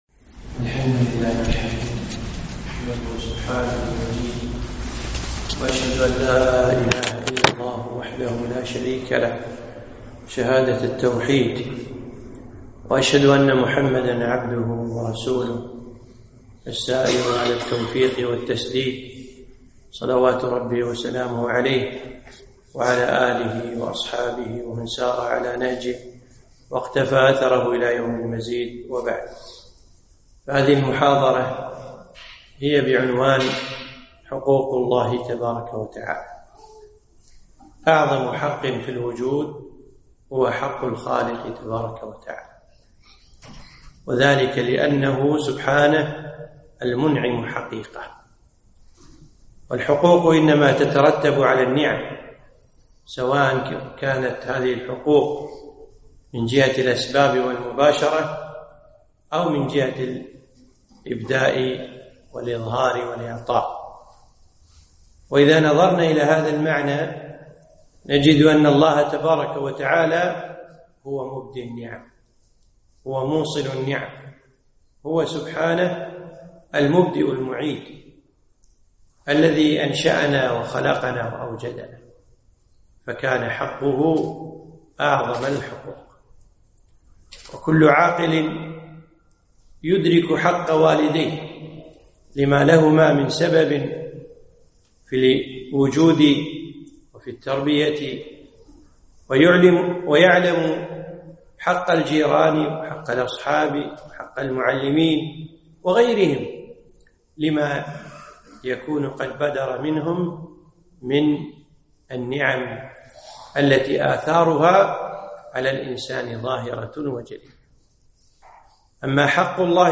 محاضرة - حق الله عز وجل على العباد